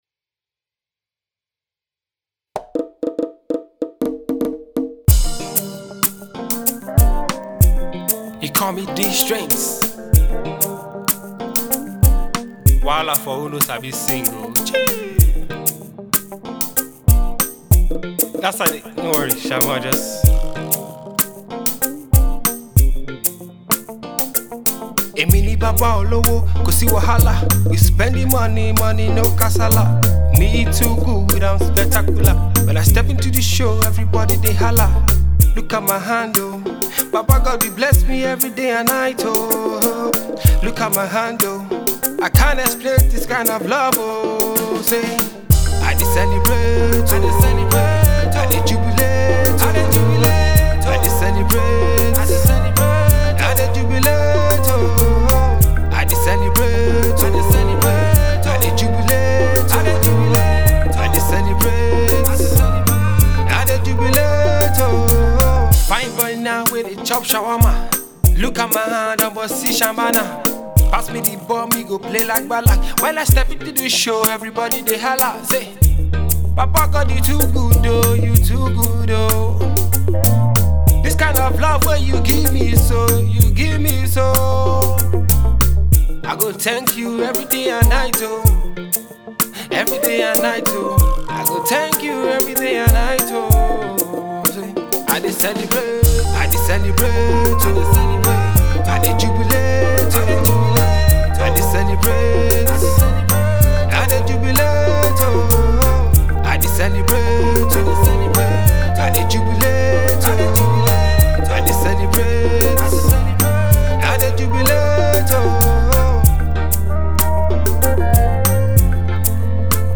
The tone of the music is that of celebration.